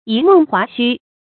一夢華胥 注音： ㄧ ㄇㄥˋ ㄏㄨㄚˊ ㄒㄩ 讀音讀法： 意思解釋： 《列子·黃帝》：「捍黃帝呴晝寢而夢，游于華胥氏之國。